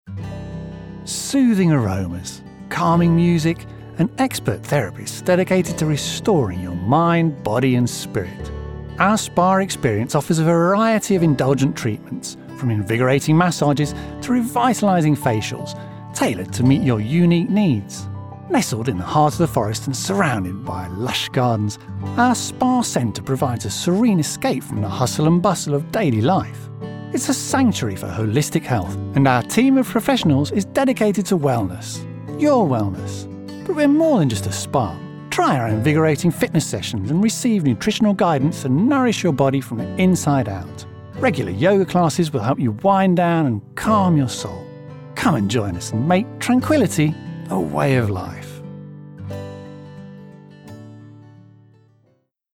Foreign & British Male Voice Over Artists & Actors
Adult (30-50) | Older Sound (50+)
0513Spa_Commercial.mp3